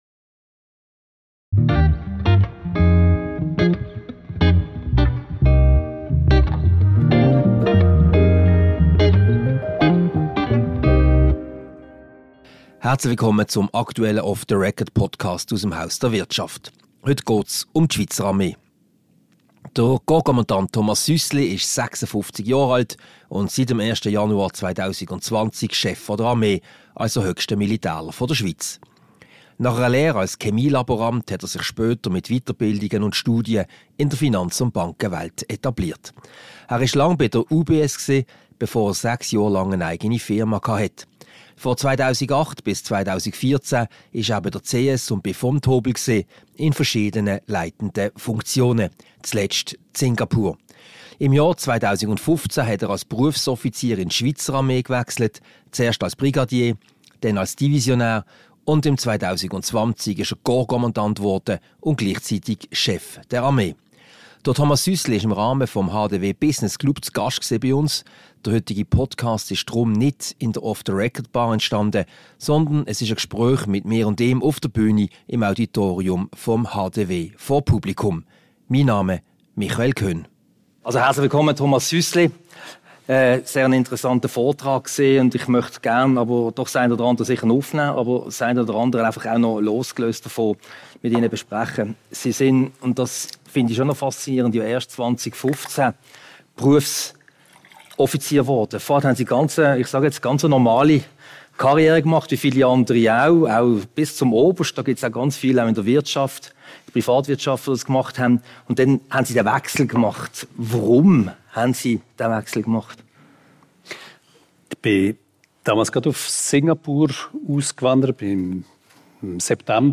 Mit Korpskommandant Thomas Süssli (Chef der Armee). Ein Gespräch über die tägliche Arbeit als Armeechef, internationale Kooperationen und die Nato sowie den Zustand der Schweizer Armee. Aufgezeichnet am HDW Business Club Dinner vom 18. Oktober.